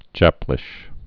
(jăplĭsh)